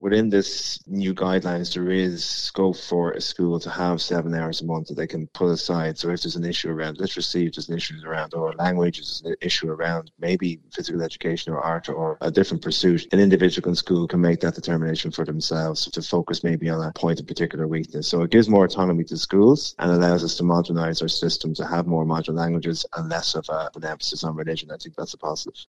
Labour’s education spokesperson, Aodhán O’Ríordáin, who’s a former primary school principal, says the changes will give schools more flexibility: